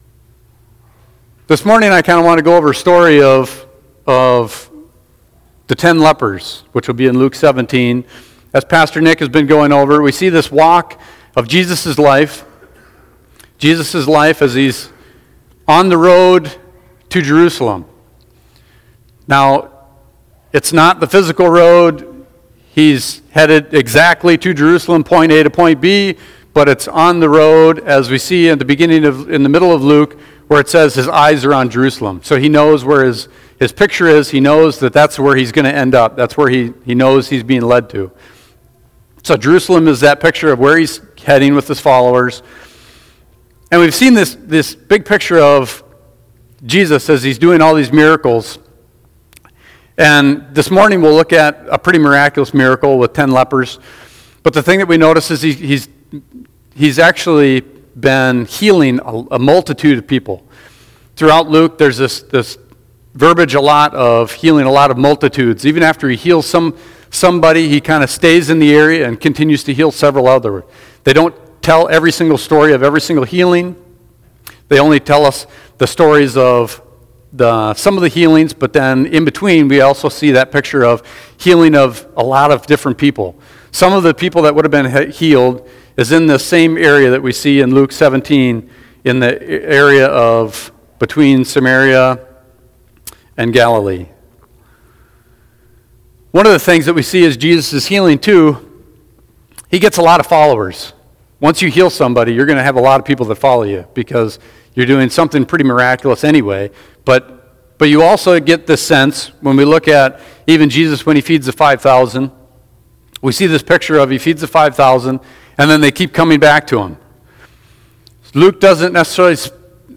Bible Text: Luke 17:11-19 | Preacher